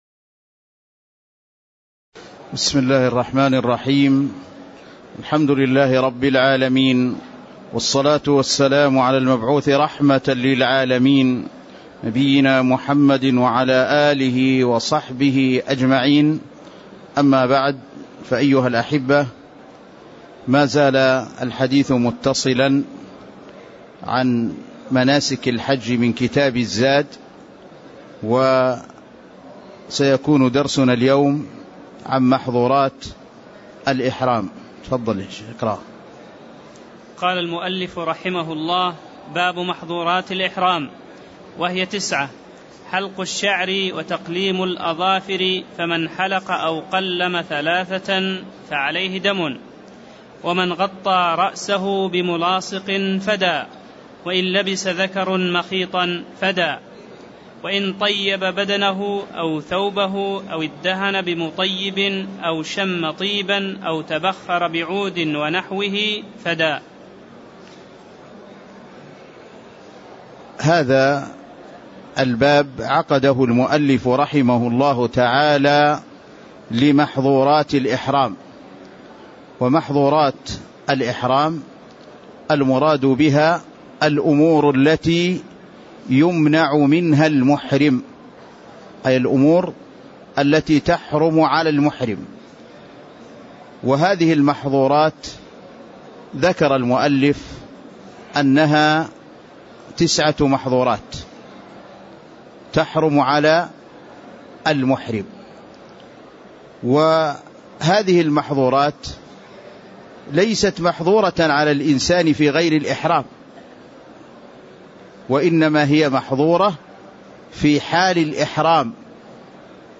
تاريخ النشر ٢٠ ذو القعدة ١٤٣٥ هـ المكان: المسجد النبوي الشيخ